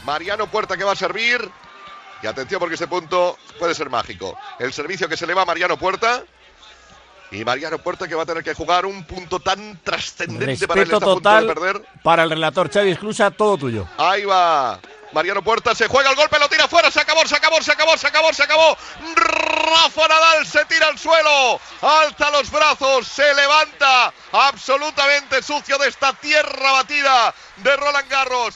Transmissió des de París de la final del torneig de tennis Roland Garros.
Declaracions de Rafael Nadal després del partit.